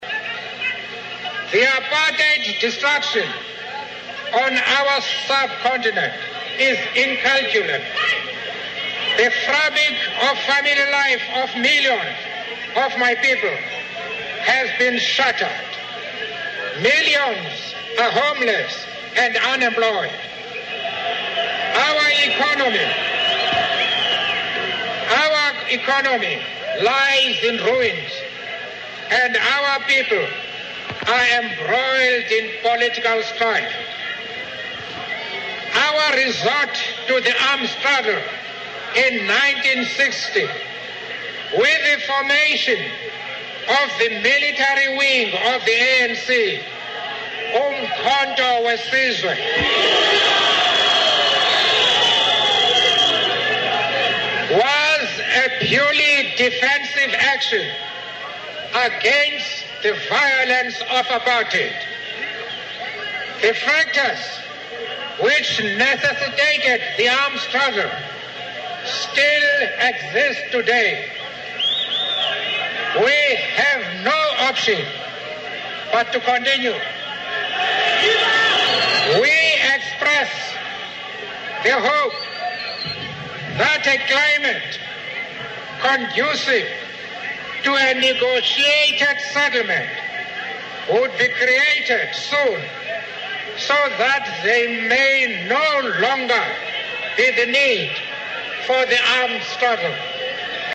名人励志英语演讲 第33期:为理想我愿献出生命(7) 听力文件下载—在线英语听力室